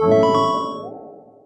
win.ogg